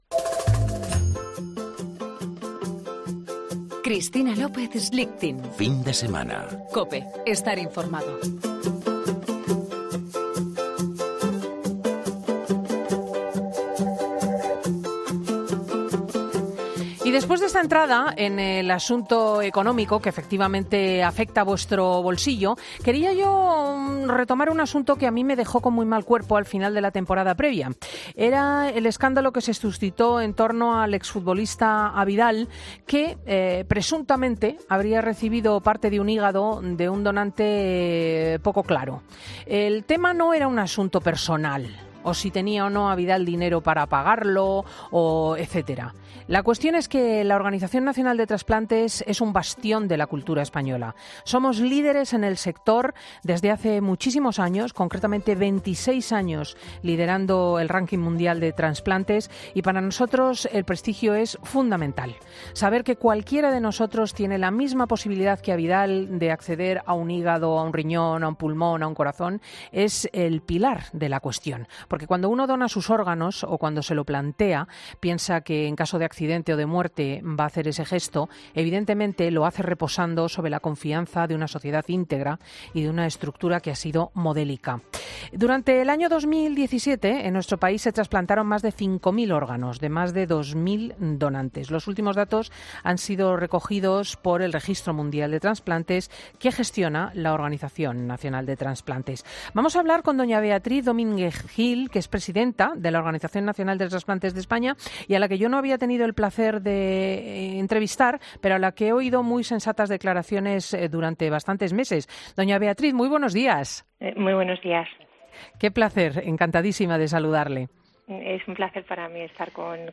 Beatriz Domínguez-Gil, presidenta de la Organización Nacional de Trasplantes de España, habla en 'Fin de Semana' sobre el sistema de trasplantes y...